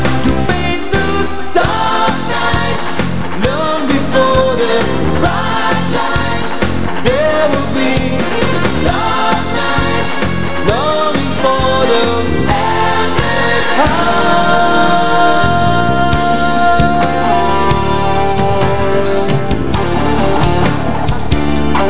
lead & backing vocals
keyboards, acoustic & rhythm guitars, bass and drums
lead & rhythm guitars
backing vocals